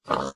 Sound / Minecraft / mob / pig1